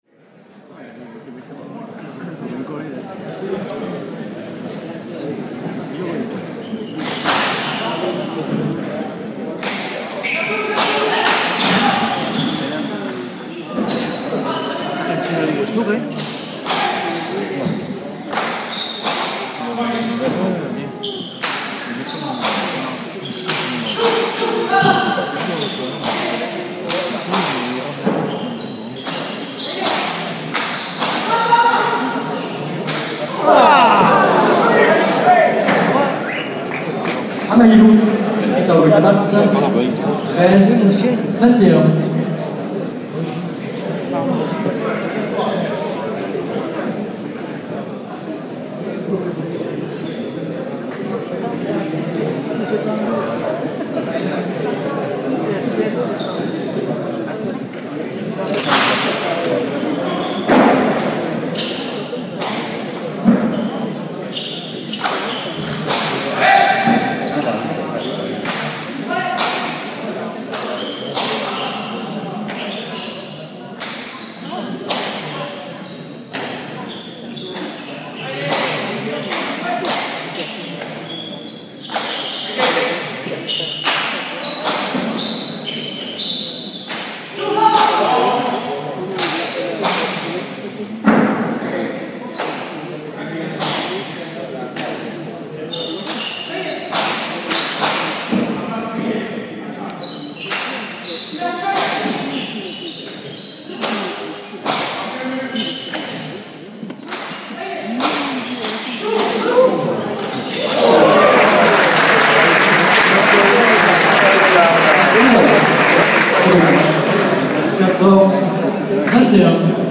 Collés aux tribunes, les cotes annoncées à la volée : « 50/20 », « 100/50 », déclinées de la tête par l’assistance parfois, négociées durement, souvent, puis finalement acceptées sans que l’on s’en rende compte.
TRINQUET1.mp3